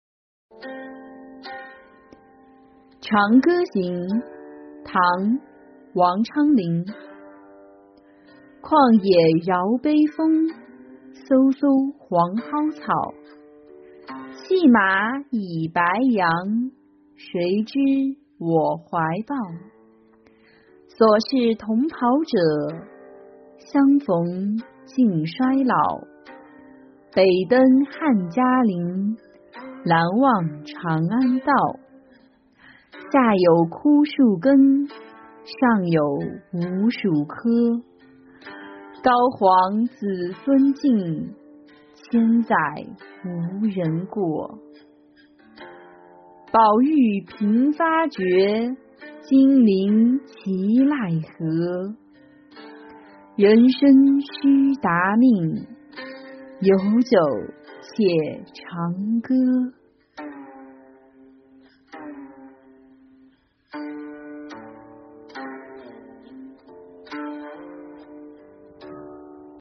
长歌行-音频朗读